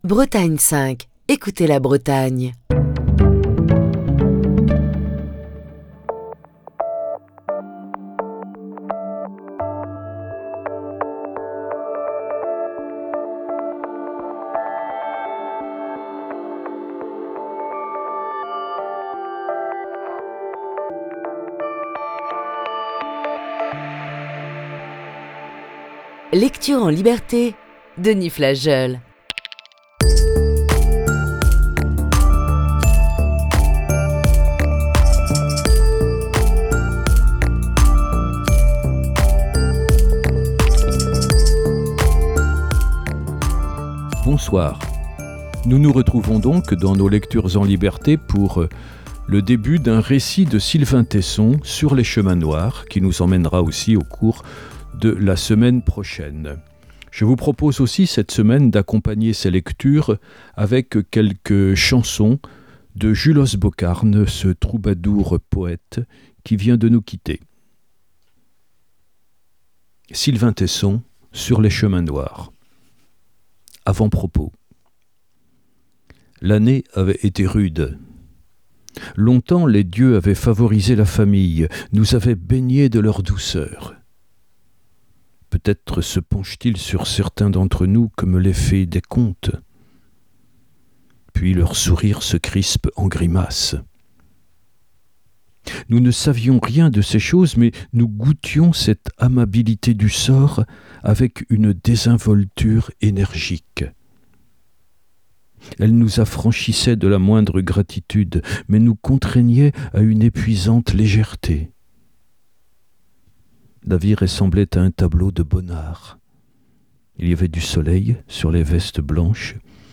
Voici ce soir la première partie de ce récit.